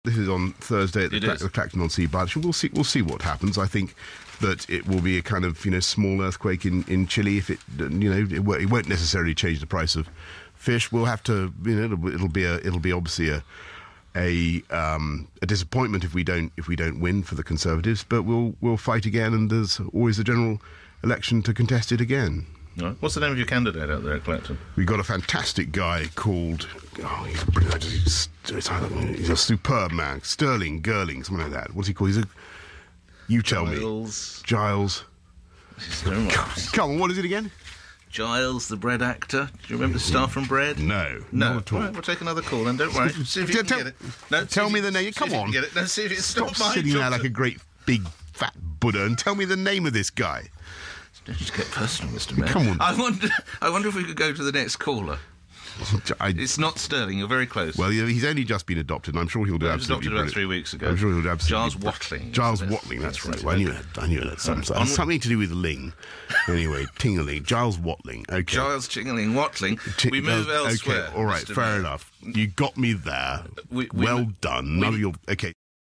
LBC Radio, 9 Oct 2014